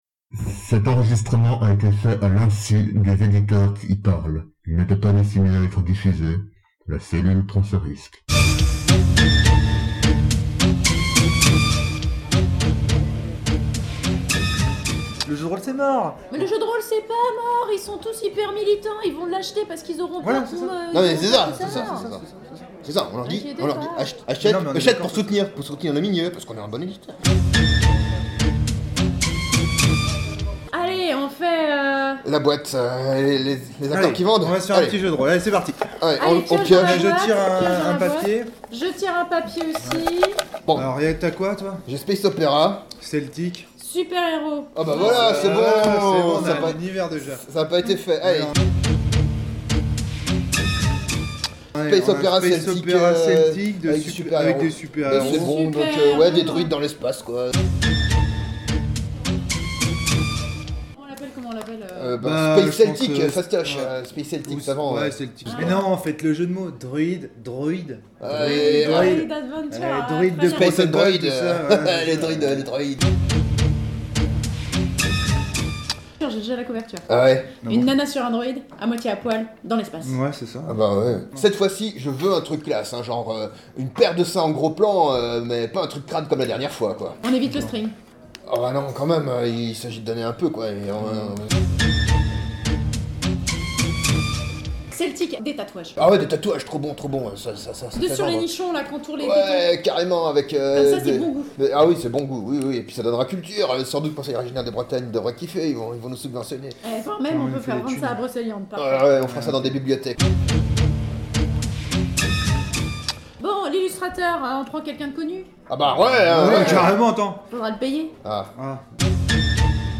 Ce loisir, qui a rendu fou plus d'un de nos contemporains, défraie une nouvelle fois la chronique avec cet enregistrement enregistré à l'insu des éditeurs enregistrés ci-dessus grâce à des moyens techniques proposés par nos téléphones intelligents.